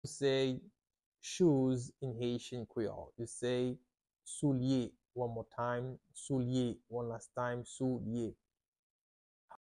How to say "Shoes" in Haitian Creole - "Soulye" pronunciation by a native Haitian tutor
“Soulye” Pronunciation in Haitian Creole by a native Haitian can be heard in the audio here or in the video below:
How-to-say-Shoes-in-Haitian-Creole-Soulye-pronunciation-by-a-native-Haitian-tutor.mp3